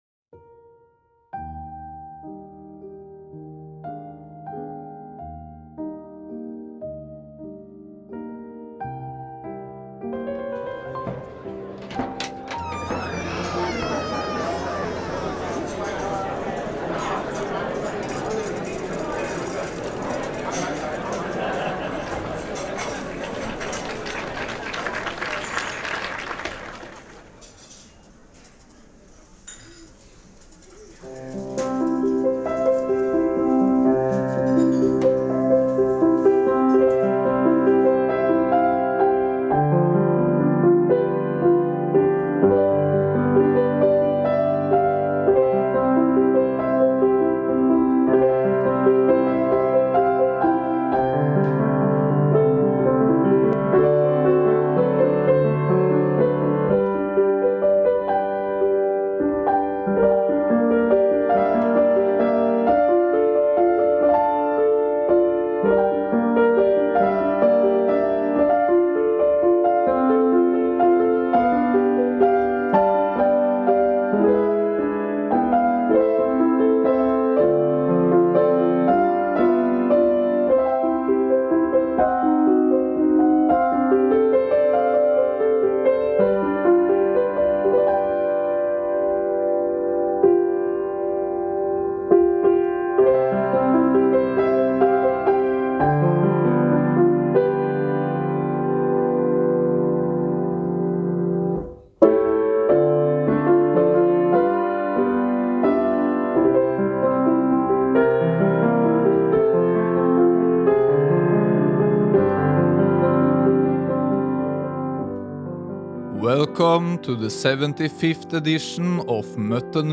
Classical Piano Special Value4Value Music Podcast https